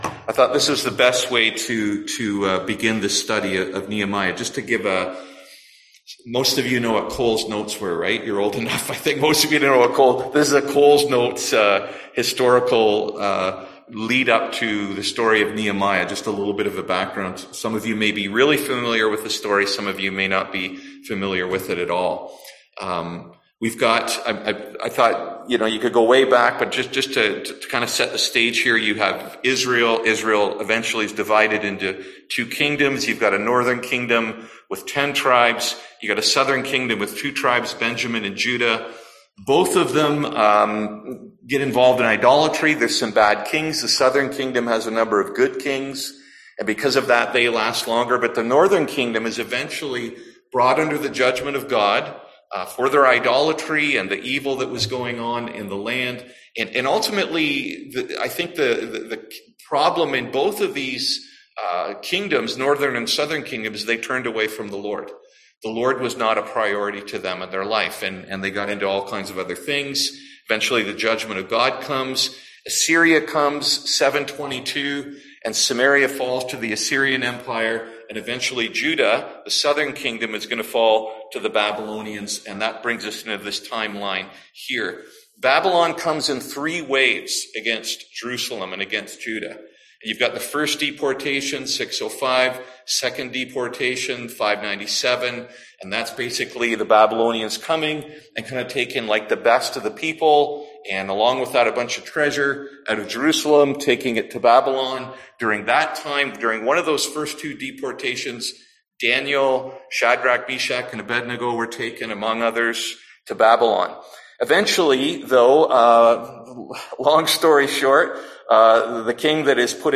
Service Type: Seminar Topics: Discouragement